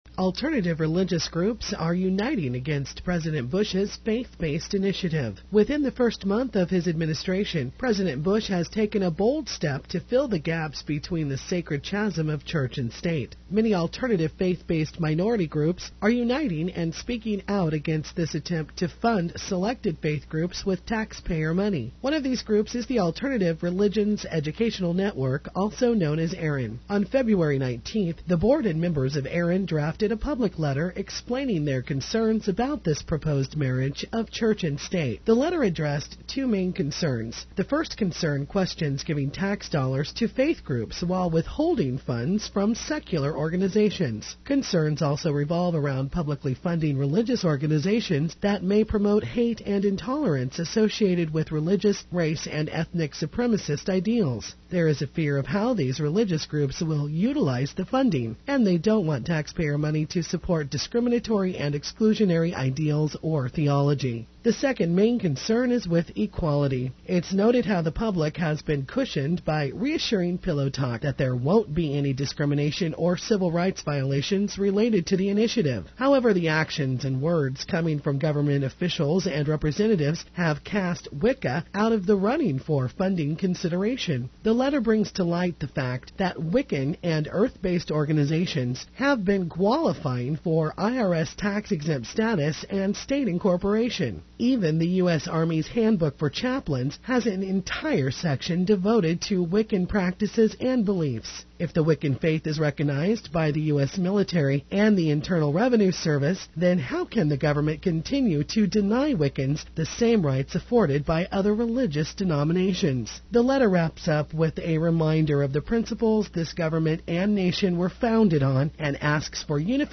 453k - MP3 1:34 read of article